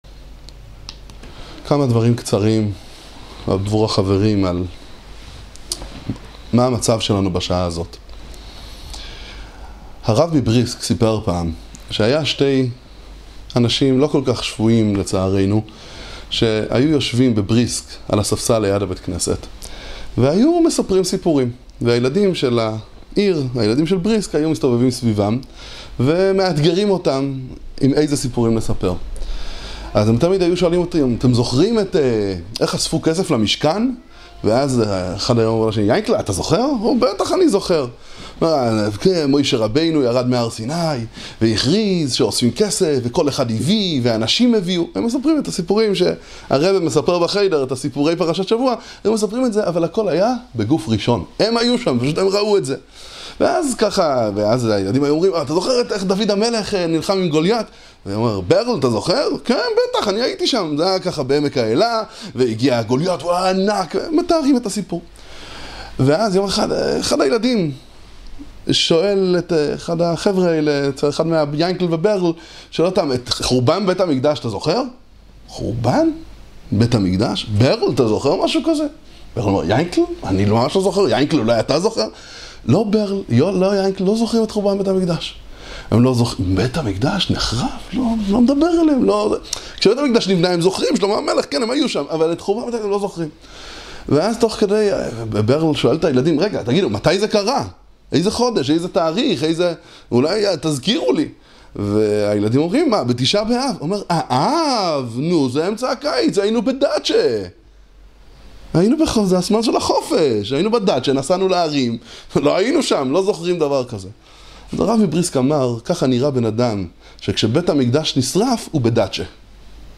דברי התעוררות לפני הלוויה